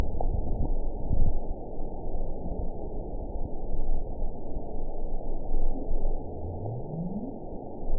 event 921691 date 12/16/24 time 22:35:05 GMT (11 months, 2 weeks ago) score 9.19 location TSS-AB03 detected by nrw target species NRW annotations +NRW Spectrogram: Frequency (kHz) vs. Time (s) audio not available .wav